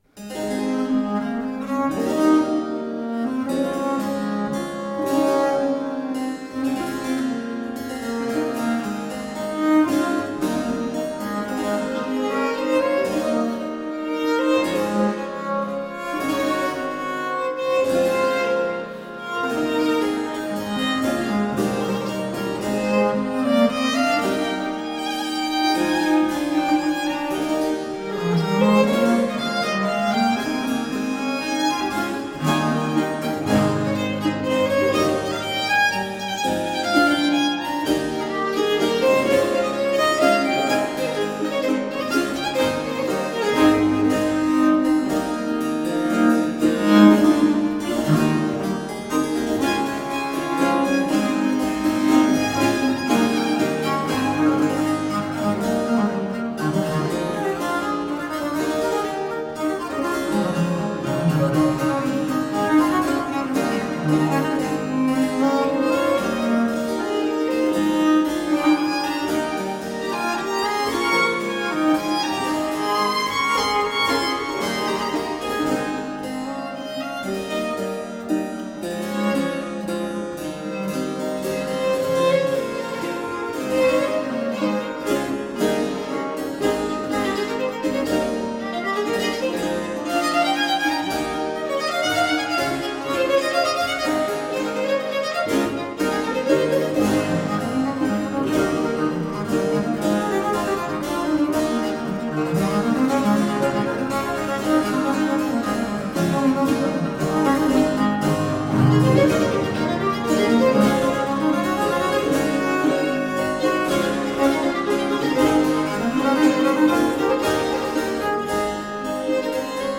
Rare and extraordinary music of the baroque.
using period instruments